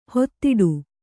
♪ hottiḍu